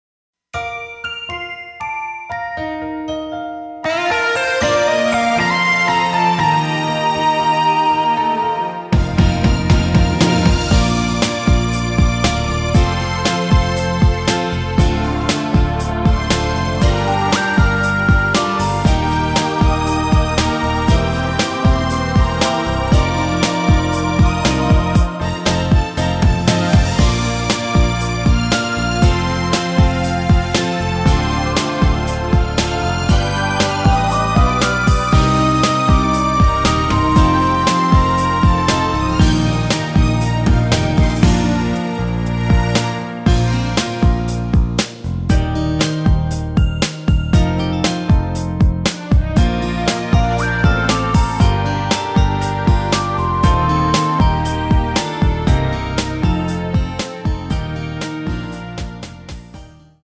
대부분의 남성분이 부르실수 있는키로 제작 하였습니다.
원키에서(-9)내린 MR입니다.
Bb
앞부분30초, 뒷부분30초씩 편집해서 올려 드리고 있습니다.